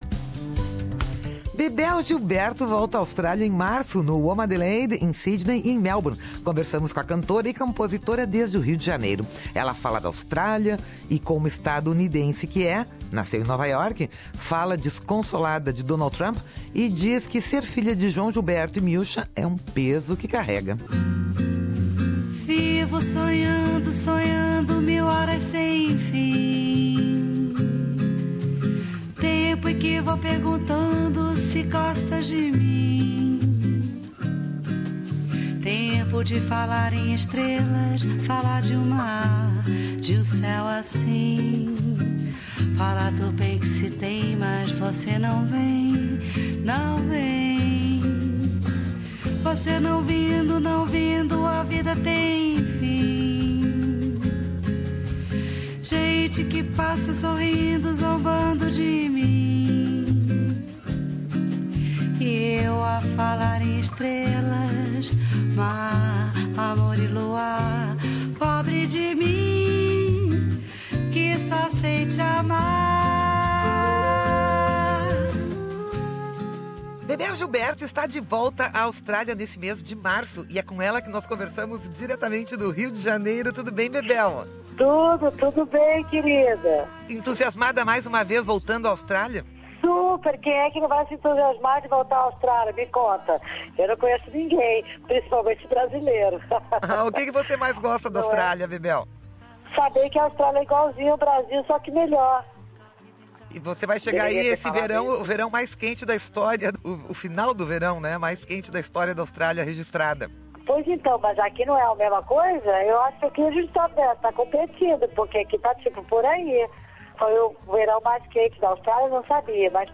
Bebel Gilberto volta à Austrália após 7 anos e nesta entrevista, como estado-unidense que é - nasceu em Nova York - fala, desconsolada, de Donald Trump, e diz que ser filha de João Gilberto e Miúcha é... um peso.